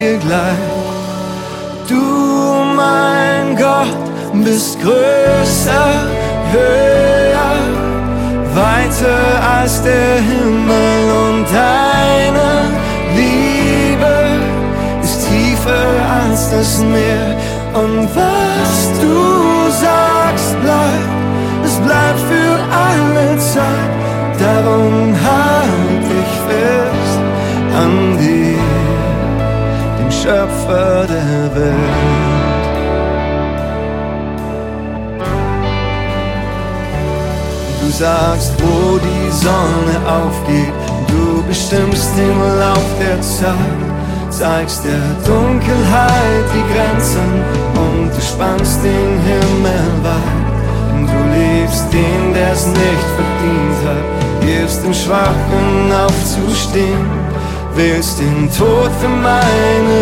Worship 0,99 €